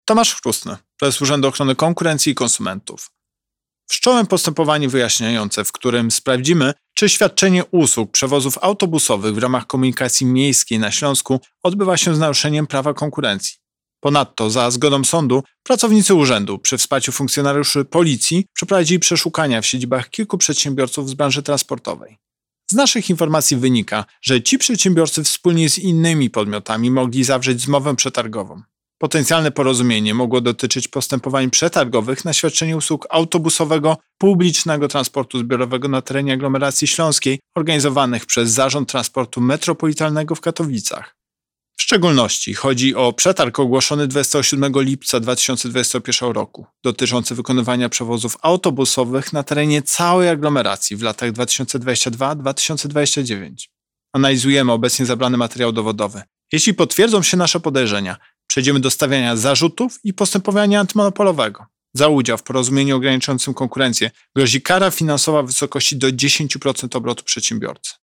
Pobierz wypowiedź Prezesa UOKiK Tomasza Chróstnego